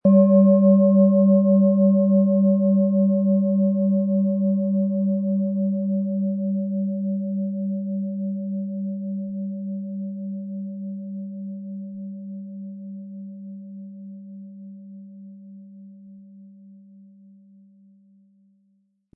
Tageston
Diese tibetische Klangschale mit dem Ton von Tageston wurde von Hand gearbeitet.
Im Sound-Player - Jetzt reinhören hören Sie den Original-Ton dieser Schale.
Der Schlegel lässt die Schale harmonisch und angenehm tönen.
MaterialBronze